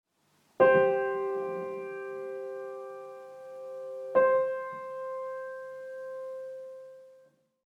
I det første eksemplet føles den øverste tonen som grunntone og i det andre eksemplet er det den nederste tonen som føles som grunntone:
Stigende kvart:
stigende-kvart.mp3